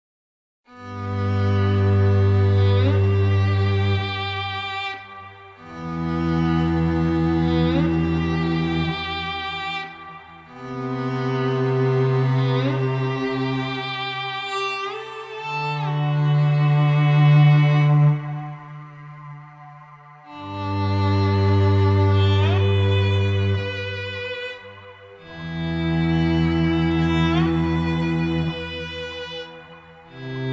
Heavenly Violin & Cello Instrumentals